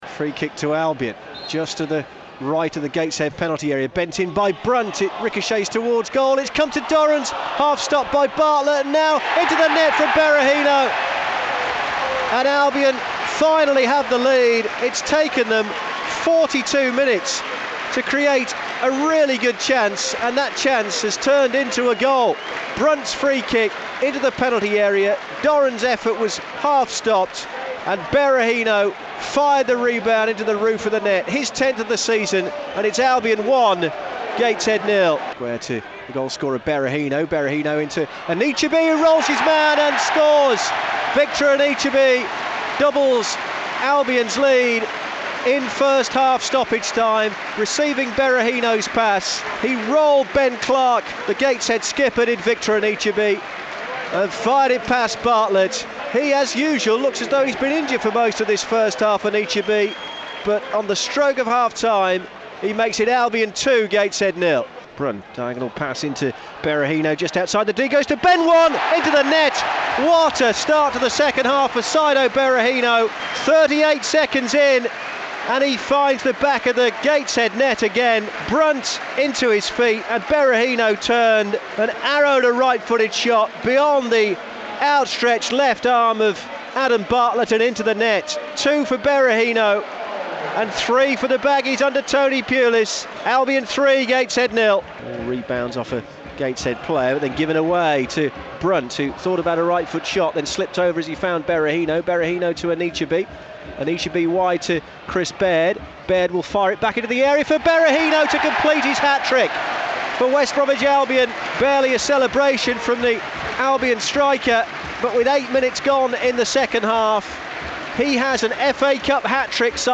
describes the action and talks to Tony Pulis.